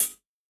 UHH_ElectroHatA_Hit-23.wav